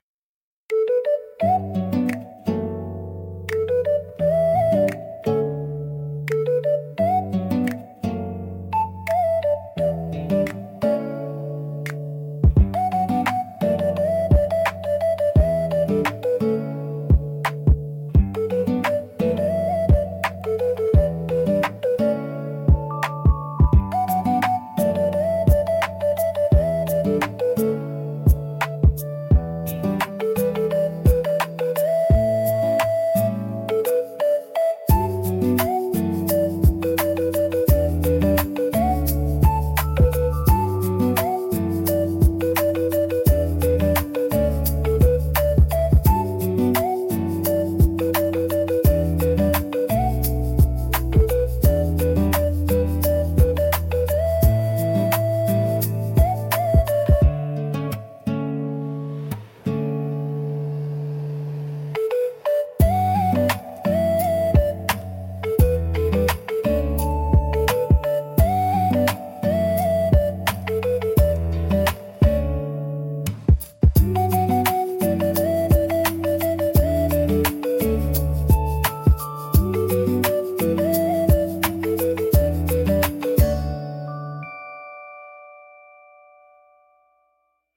POPS
ほのぼの , ポップス , リコーダー , 日常 , 春 , 朝 , 穏やか , 静か